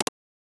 Click (13).wav